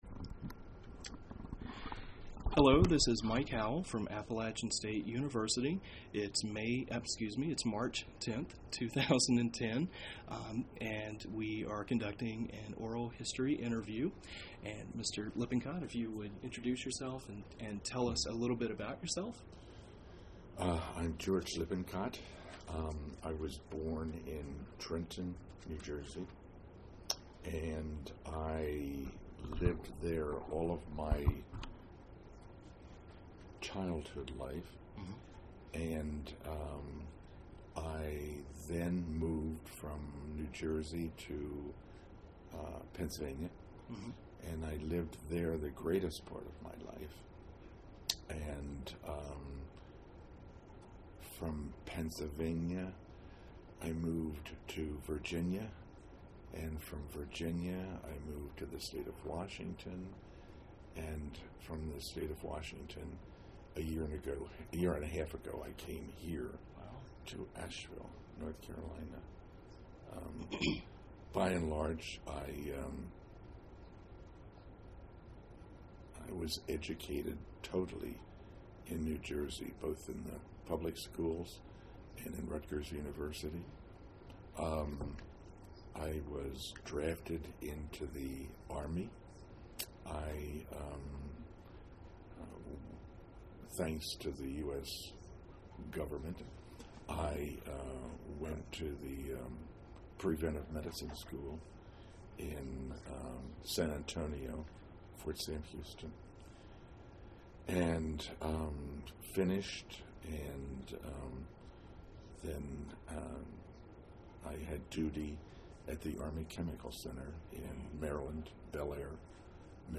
Appalachian Lesbian, Gay, Bisexual, and Transgender Oral History Project